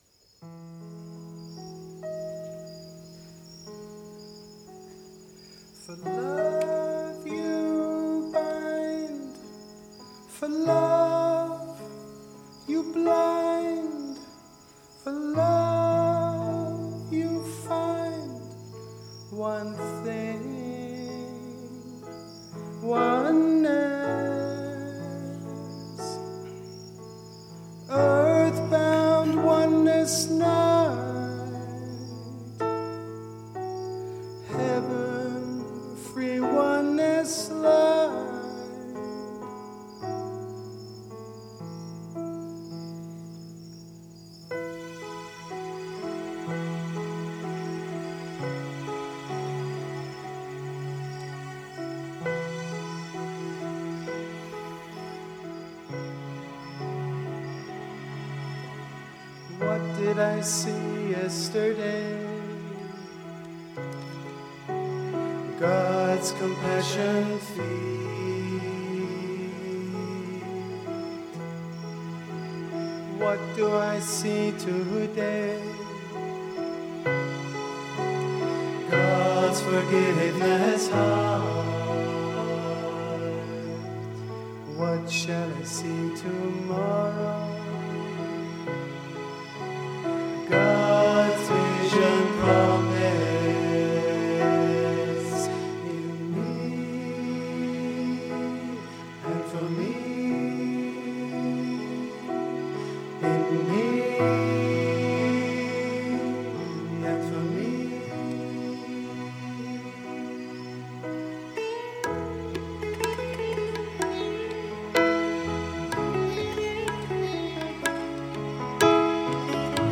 A selection of recordings from Sri Chinmoy’s Birthday Celebrations of August 2014. Featuring performances by Sri Chinmoy’s students who offer us an array of musical performances featuring different interpretations and arrangements of his songs.